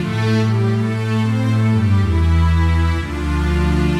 Index of /musicradar/80s-heat-samples/120bpm
AM_80sOrch_120-A.wav